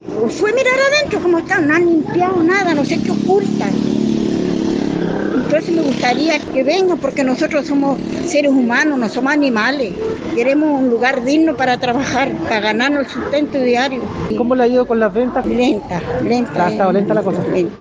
“Me gustaría que vengan, nosotros somos humanos no somos animales. Queremos un lugar digno para trabajar, para ganarnos nuestro sustento diario (…)”, expresó la mujer.